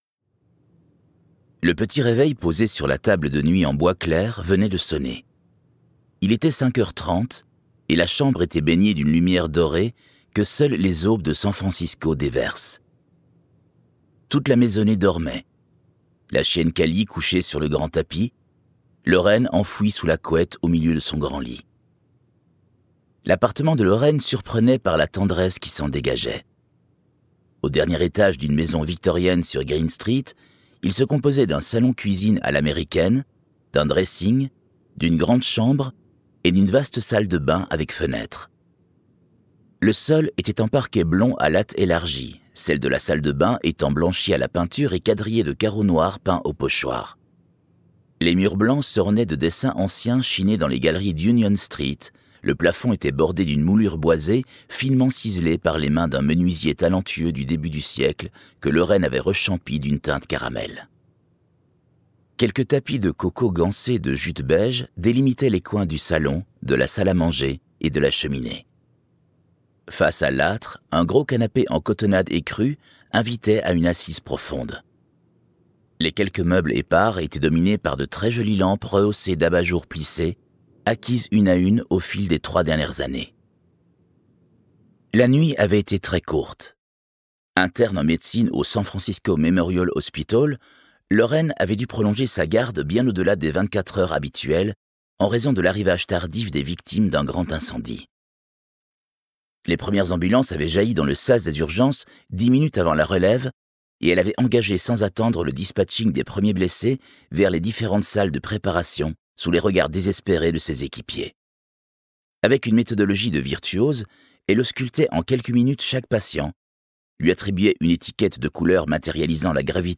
Et si c'était vrai - le plus grand succès de Marc Levy en audiodrama suivi d'un entretien avec l'auteur
Get £5.10 by recommending this book 🛈 Une histoire d'amour insolite et bouleversante qui a conquis le monde. Des comédien.nes distincts pour incarner chaque personnage, un accompagnement sonore sur-mesure....
Dans le décor d'un San Francisco lumineux bercé par la rumeur de l'océan, une expérience audio unique où les héros prennent vie.